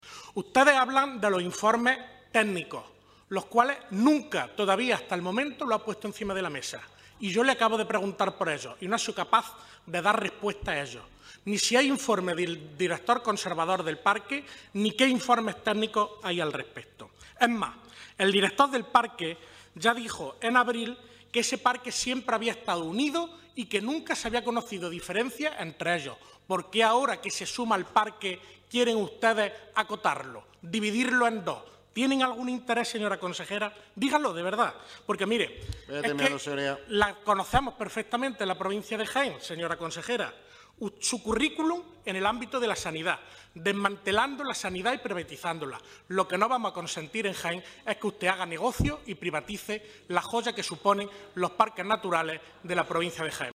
Comisión de Sostenibilidad y Medio Ambiente
Cortes de sonido
Victor-Torres.mp3